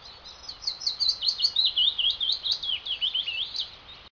Willow Warbler
cascading rippling song is one of the iconic sounds of a British spring.
WillowWarbler.ogg